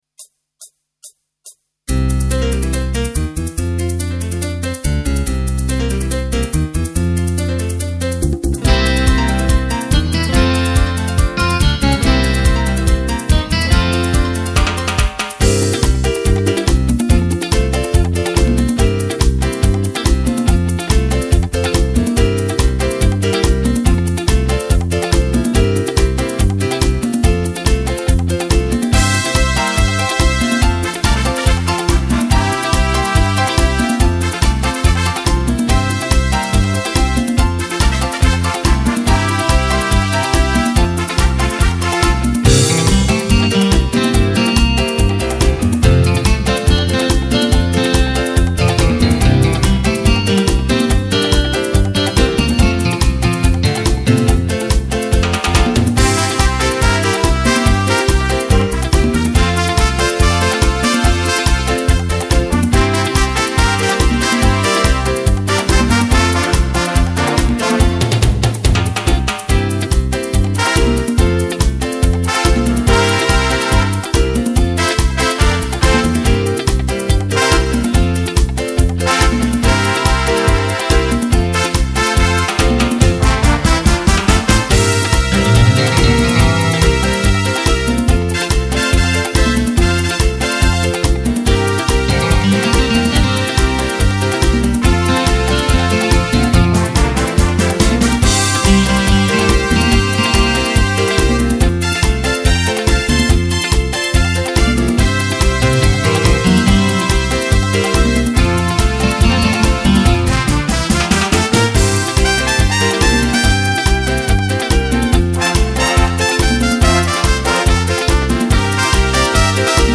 Pianoforte, orchestra